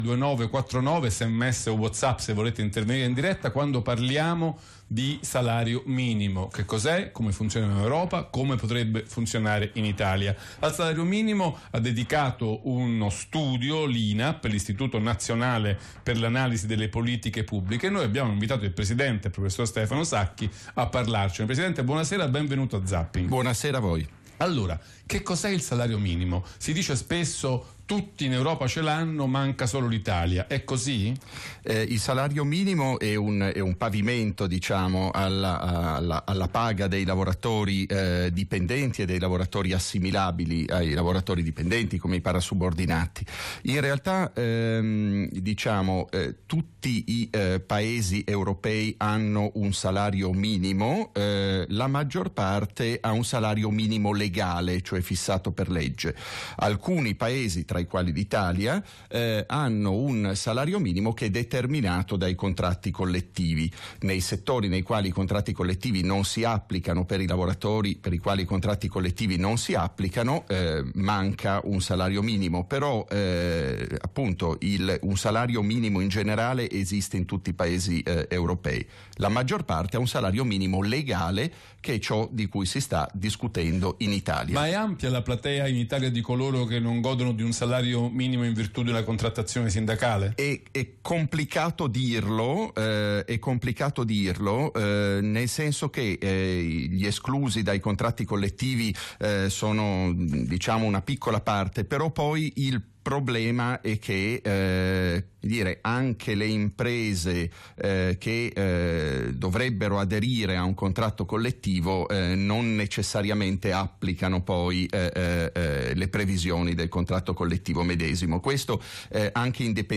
Ospite in studio il Presidente Stefano Sacchi per parlare di salario minimo partendo dallo studio INAPP